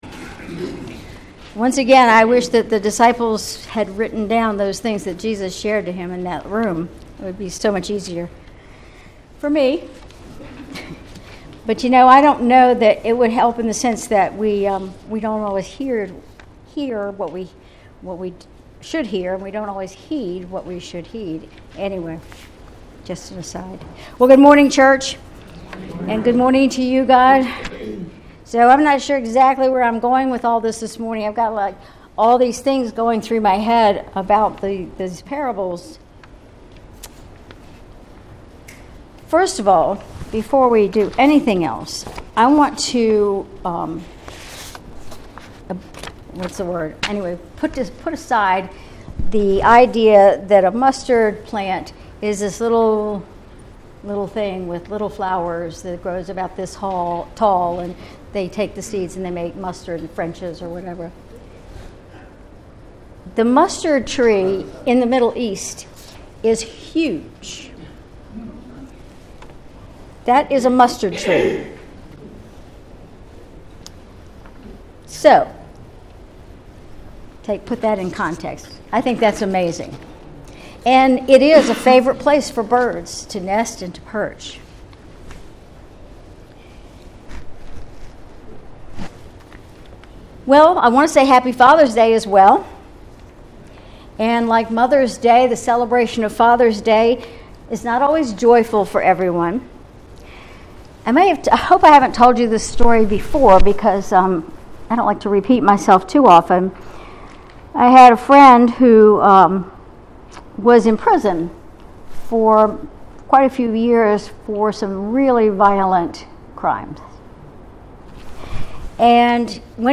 Sermon June 16, 2024